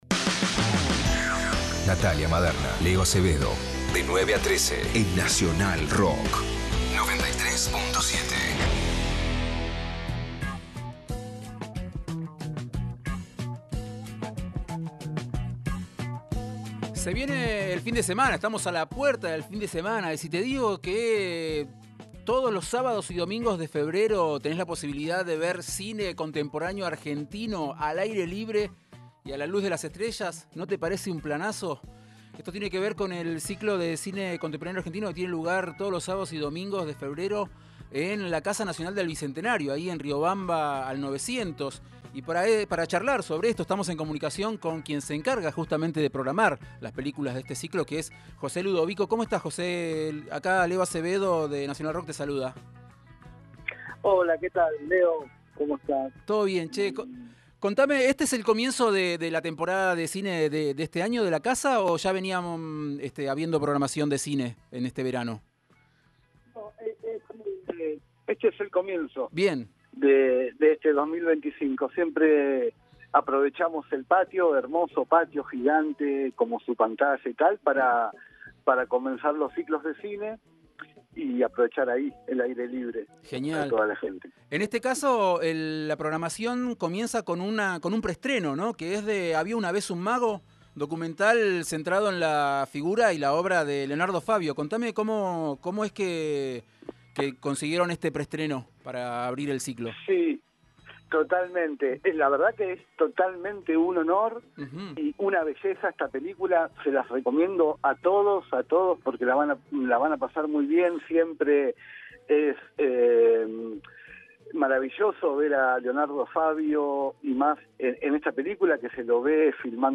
ENTREVISTA Cine al aire libre en la Casa del Bicentenario